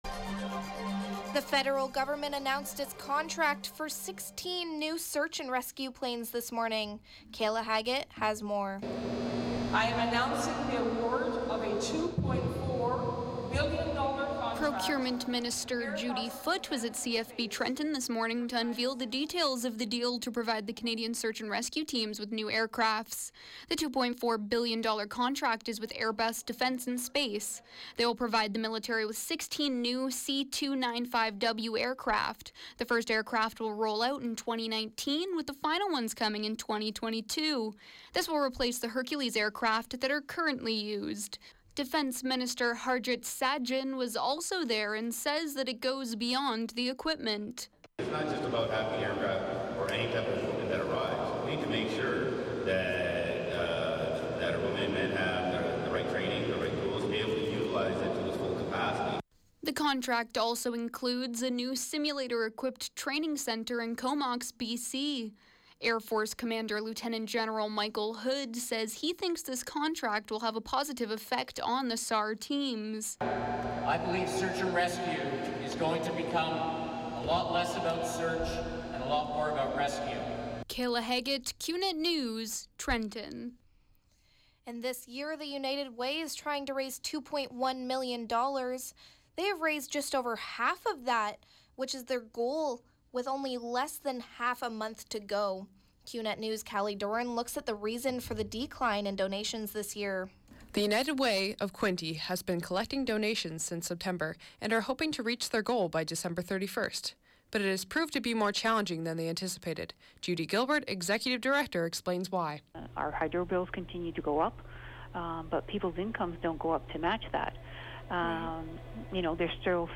91X Newscast- Thursday, Dec. 8, 2016, 4 p.m.